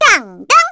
Luna-Vox_Victory_02_kr.wav